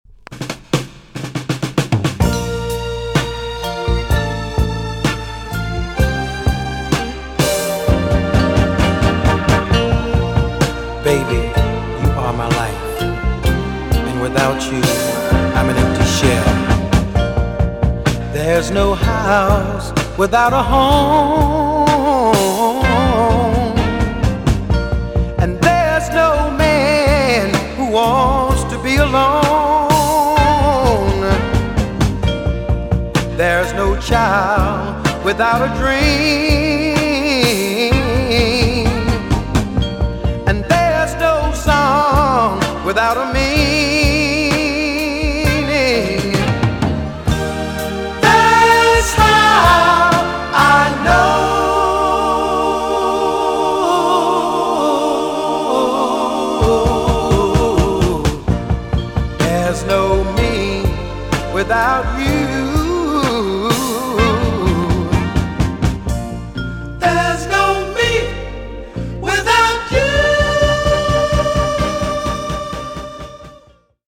TOP >JAMAICAN SOUL & etc
EX 音はキレイです。